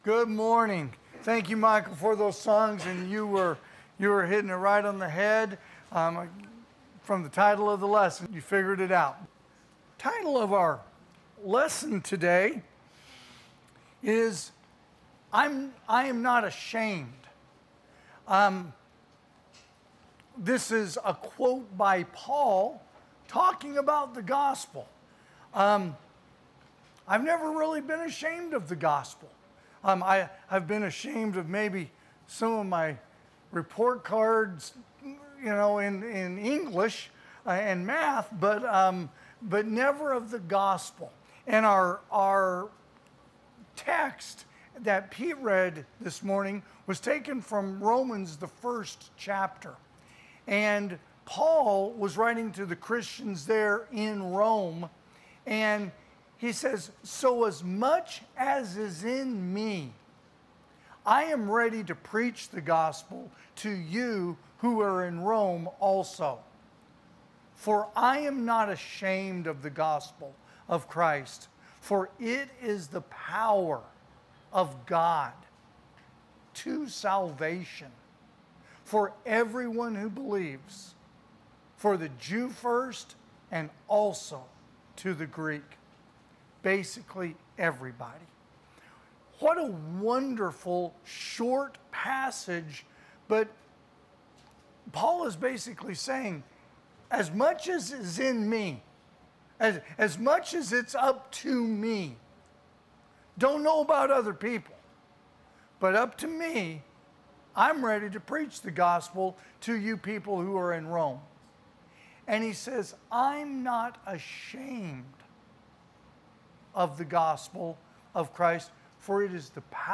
Sermons
(AM Worship)